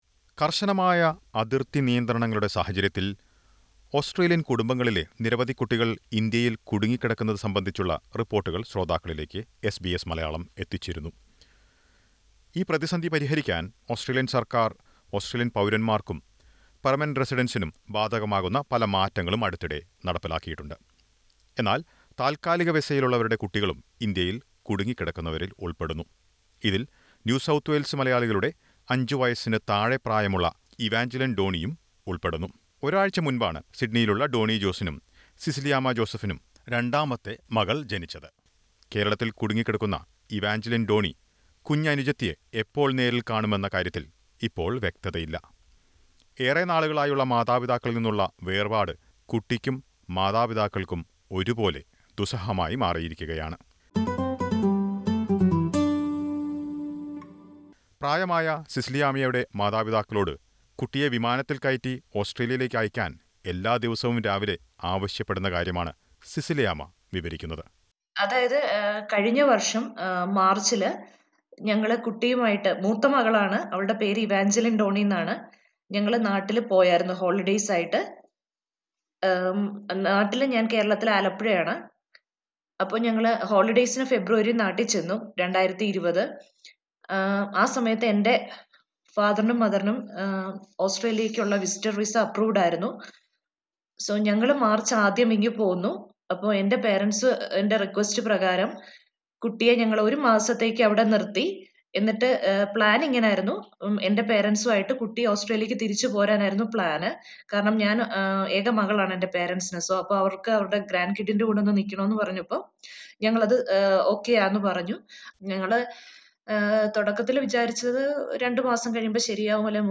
ഇന്ത്യയിൽ കുടുങ്ങിക്കിടക്കുന്ന ഓസ്‌ട്രേലിയൻ കുടുംബങ്ങളിലെ കുട്ടികളെ തിരിച്ചെത്തിക്കാൻ ഫെഡറൽ സർക്കാർ പല ഇളവുകളും നൽകിയിട്ടുണ്ട്. എന്നാൽ താത്കാലിക വിസയിലുള്ളവർക്ക് ഇത് ബാധകമല്ല. മകളെ തിരിച്ചെത്തിക്കാനുള്ള ശ്രമങ്ങൾ പരാജയപ്പെട്ട മലയാളി കുടുംബം അതിന്റെ വിശദാംശങ്ങൾ എസ് ബി എസ് മലയാളത്തോട് പങ്കുവക്കുന്നു.